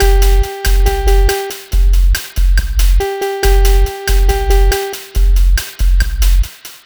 Cheese Lik 140-G.wav